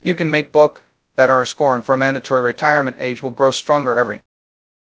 warren-buffett-speech